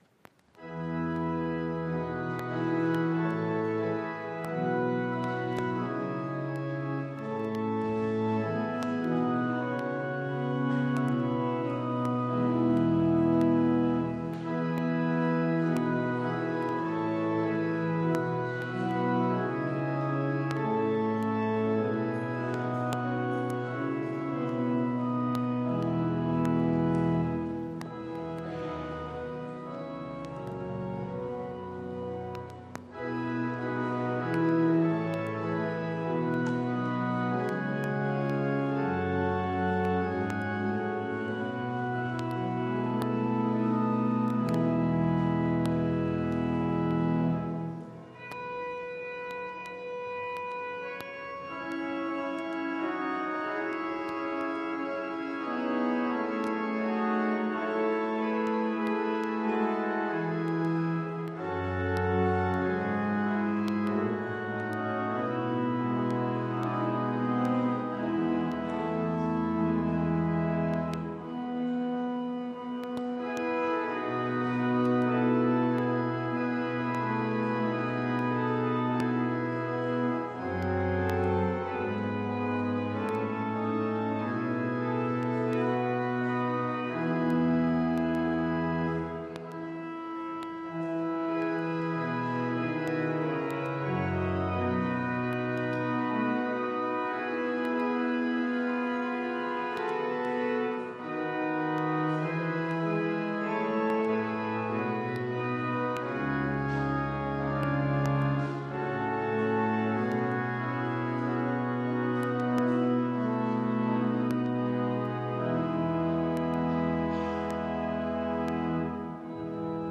Gottesdienst am 26.12.2023
Orgelstück zum Ausgang
Audiomitschnitt unseres Gottesdienstes am 2. Christtag 2023